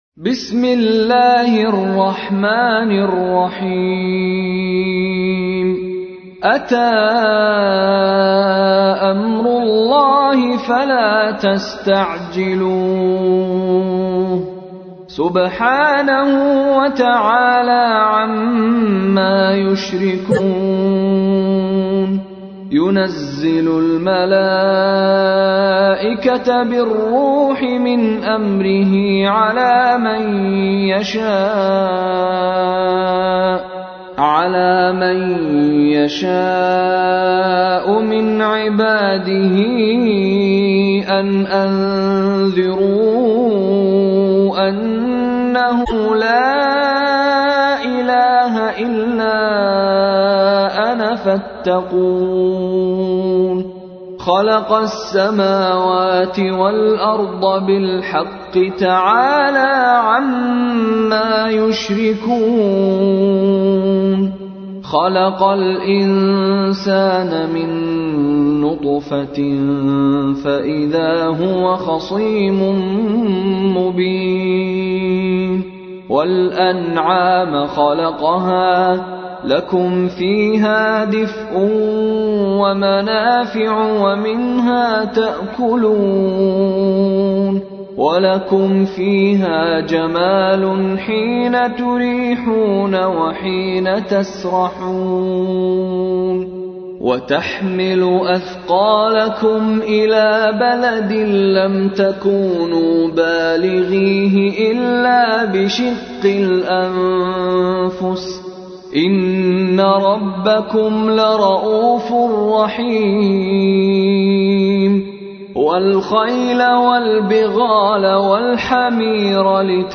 تحميل : 16. سورة النحل / القارئ مشاري راشد العفاسي / القرآن الكريم / موقع يا حسين